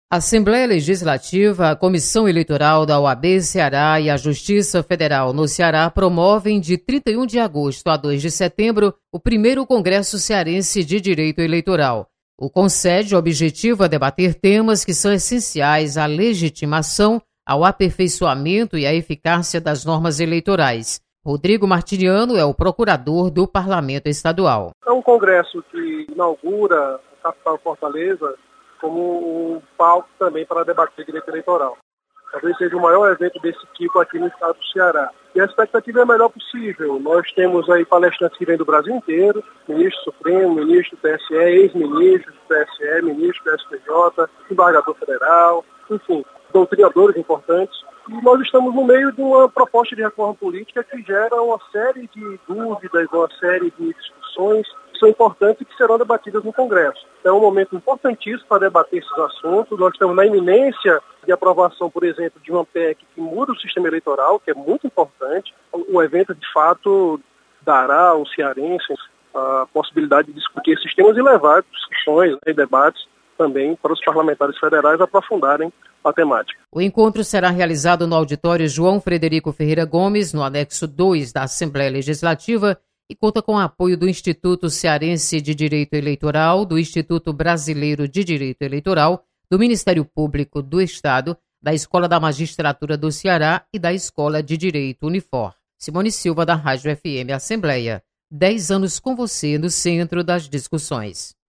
Congresso sobre direito eleitoral será realizado na Assembleia Legislativa. Repórter